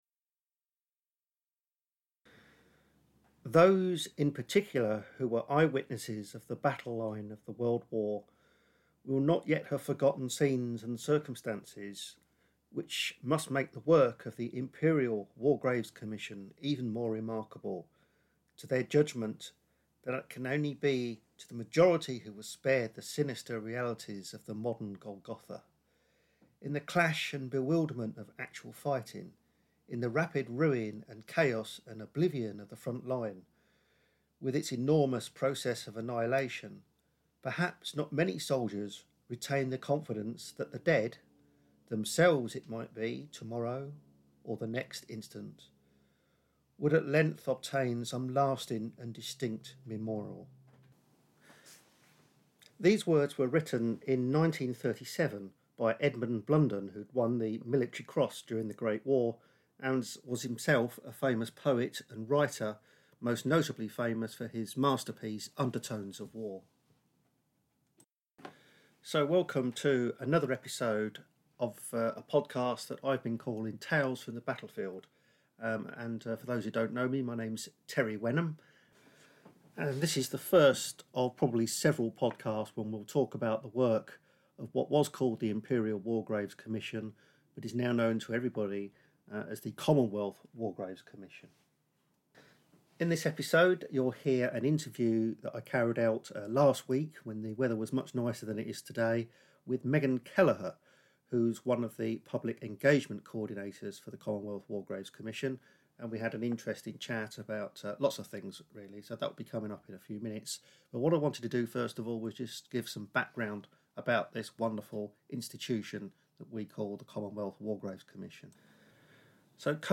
Facebook Twitter Headliner Embed Embed Code See more options In this episode we look at how a 45 year old man, Fabian Ware, set up the Commonwealth War Graves Commission and we discuss the stories of the youngest and oldest soldiers who are commemorated by the Commission. The podcast also includes an interview